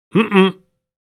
Man’s Disapproving Mmm-mmm Sound Effect
Mans-disapproving-mmm-mmm-sound-effect.mp3